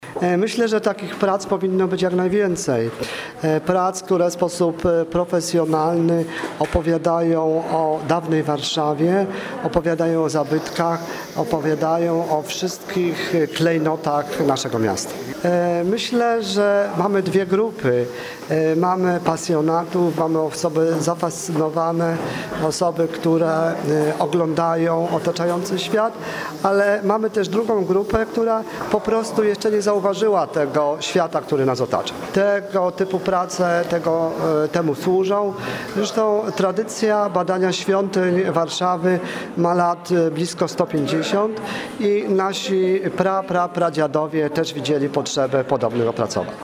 Prof. Jakub Lewicki, mazowiecki wojewódzki konserwator zabytków, zaznacza, że bardzo dobrze, że tego typu książki powstają.
Wypowiedź prof. Jakuba Lewickiego: